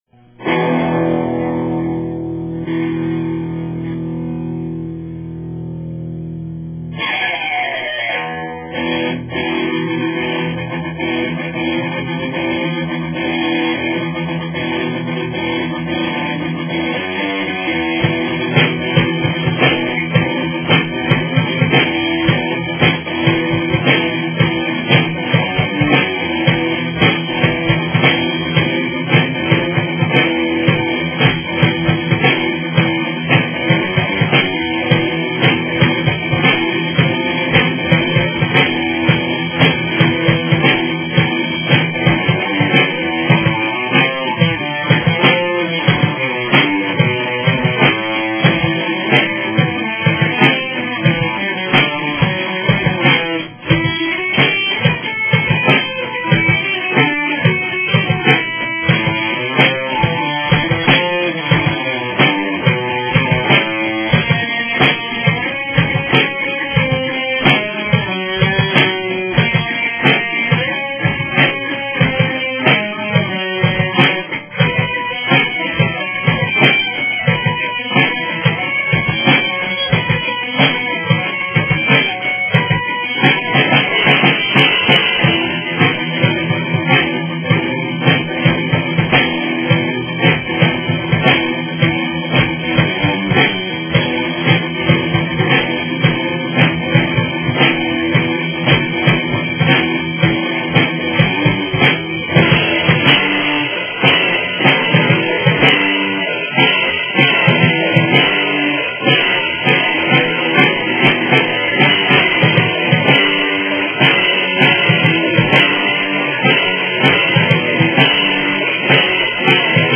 i2 (solo song) - just a whole lot of diffrent solos put into the same song, theres no lyrics but the song it self says it all, well just listen to it and youll see.... um, well hear. comments